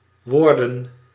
Ääntäminen
Ääntäminen Tuntematon aksentti: IPA: /ˈʋɔːrdən/ Haettu sana löytyi näillä lähdekielillä: hollanti Käännöksiä ei löytynyt valitulle kohdekielelle.